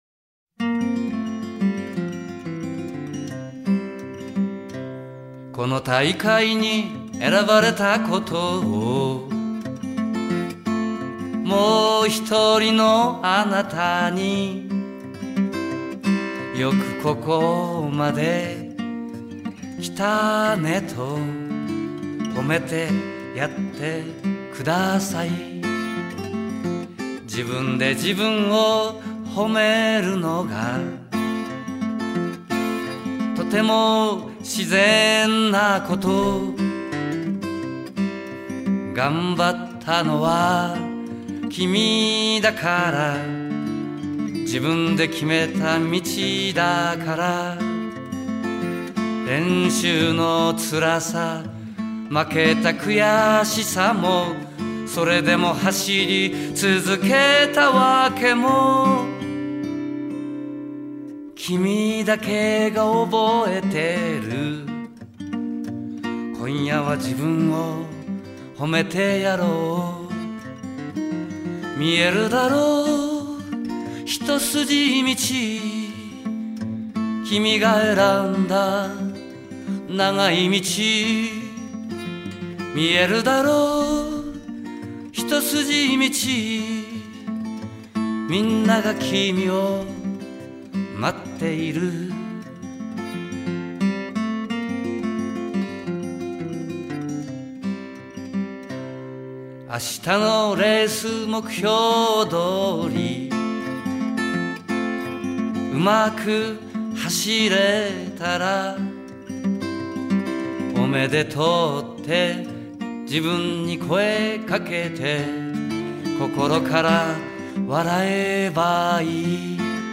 ちなみに、Key Ｆ、5capo Ｃです。